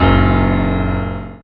55y-pno07-c#3.aif